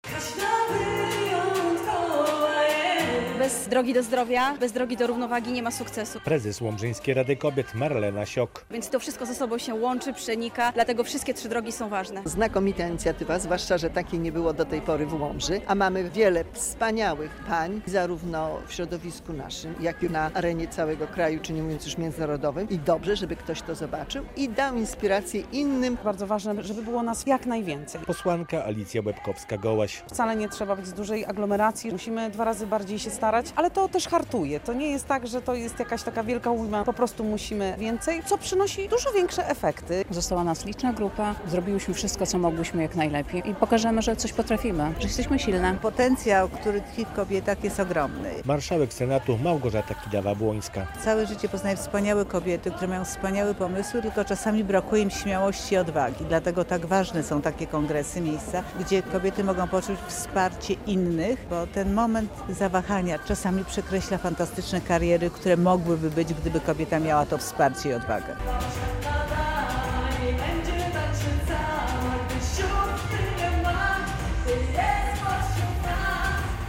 Forum kobiet w Łomży - relacja